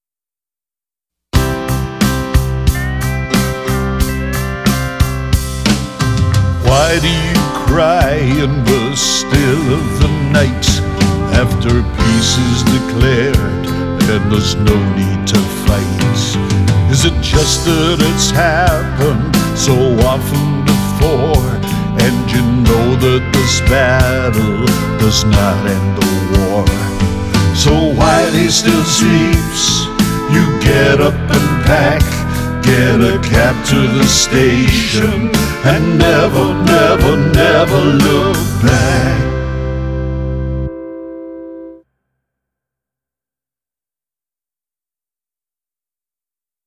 Let’s revisit the country-ish lyric That’s All the Reason You Need we created in the Songwriting 5 blog post:
I’m going to write a very basic melody based on chords  C  G  F  G for parts 1 and 2, then change the accompaniment to Am  Em7  Dm  G  for part 3 while singing the same melody.
On top of that, I’ll lay down some harmonies on part 3 and put in a wee touch of repetition to add even more variation.